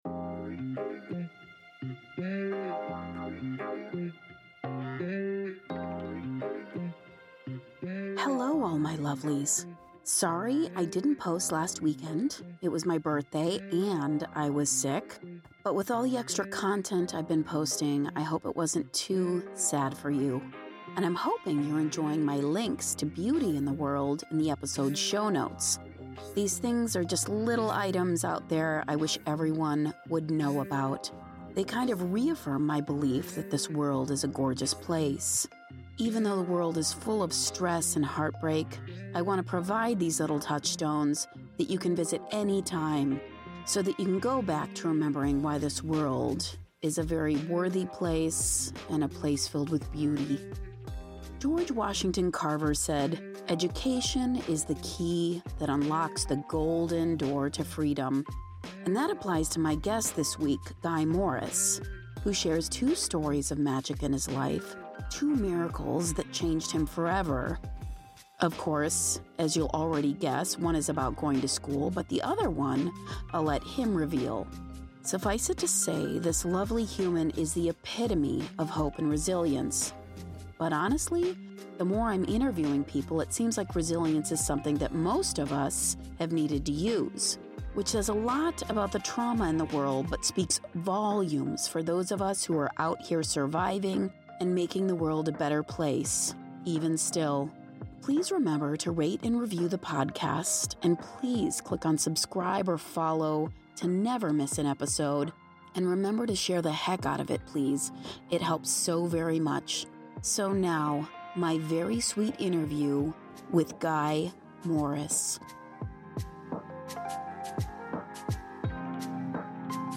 Byte Sized Blessings / The Interview